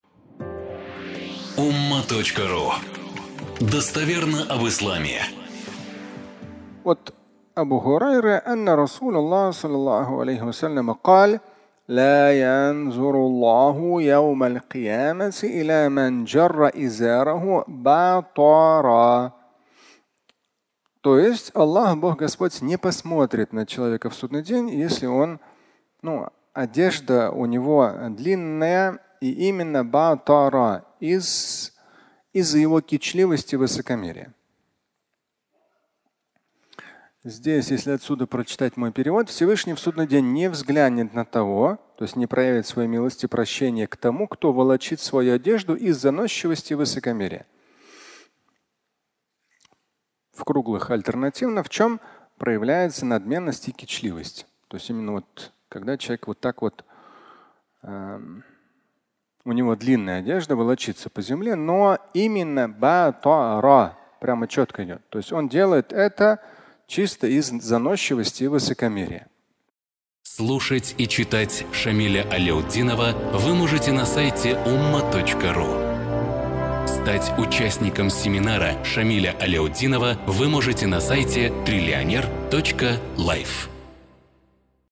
Брюки и горделивость (аудиолекция)